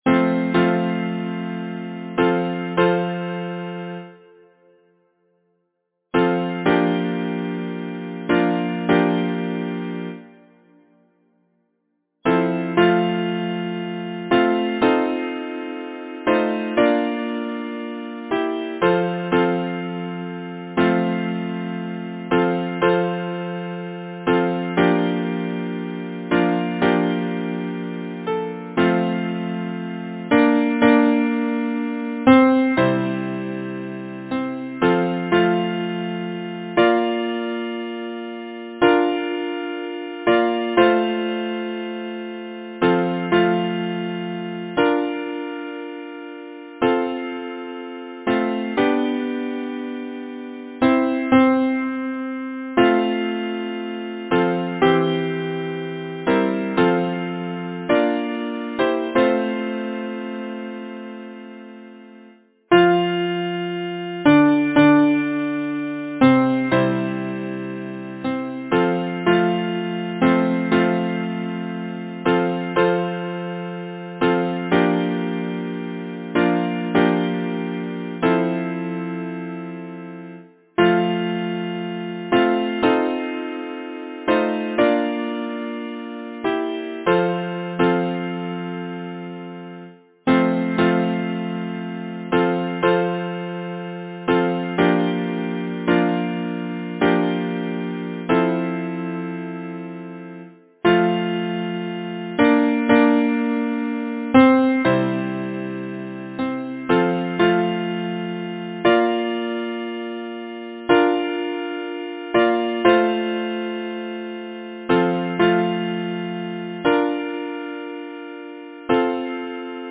Title: My Heart is sair Composer: Henry Elliot Button Lyricist: Robert Burns Number of voices: 4vv Voicing: SATB Genre: Secular, Partsong
Language: English Instruments: A cappella